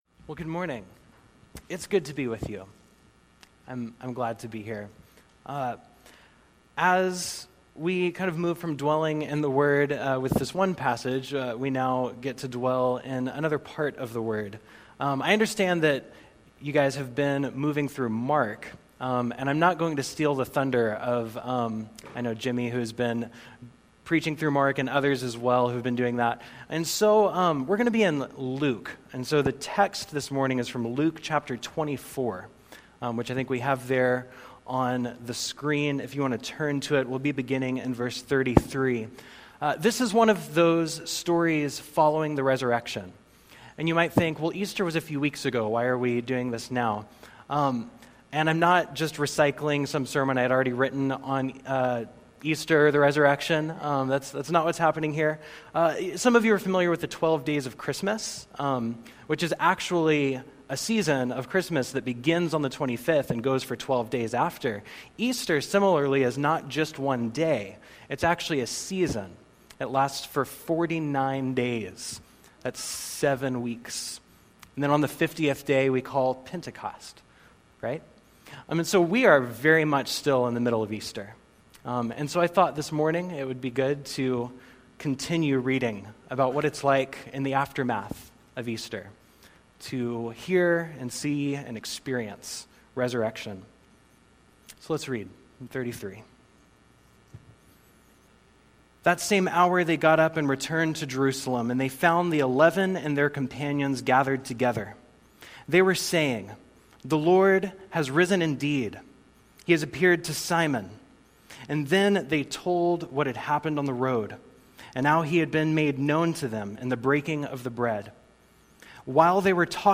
In the spring of 2018, I was invited to preach at Federal Way Church of Christ.